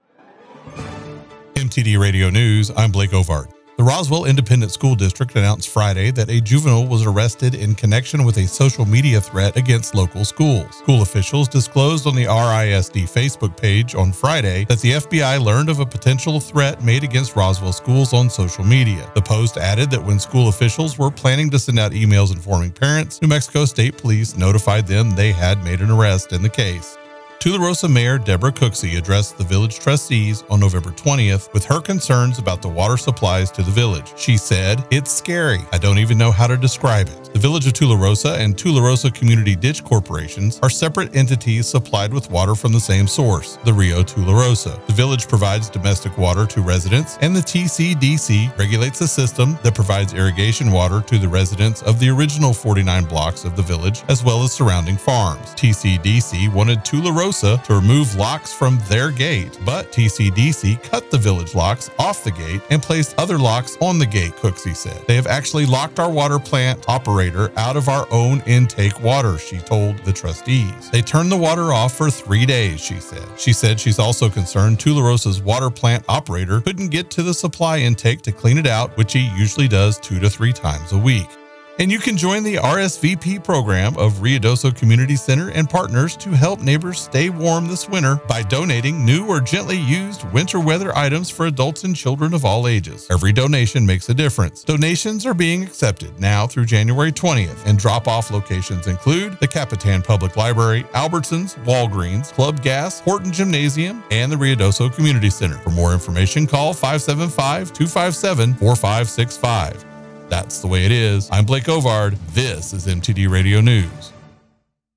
KIDX News November 25, 2024